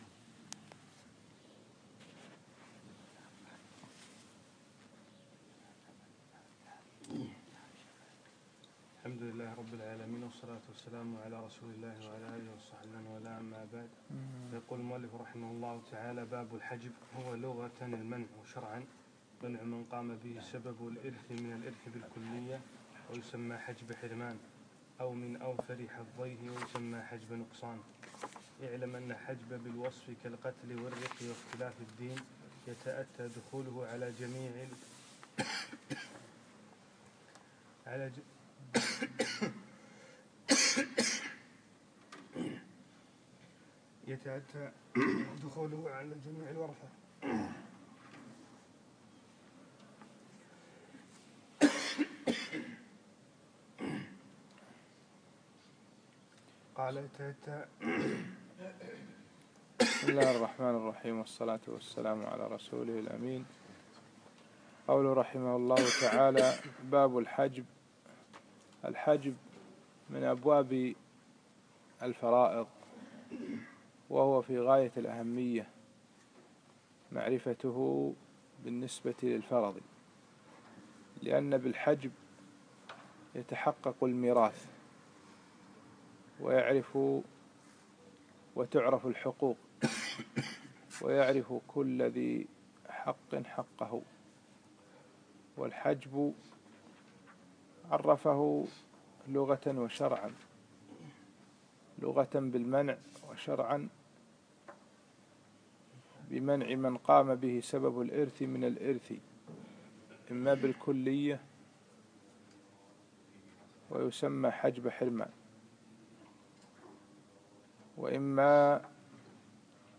يوم الأحد 25 جمادى الثاني 1437هـ الموافق 3 4 2016 في مسجد الزبن عبدالله المبارك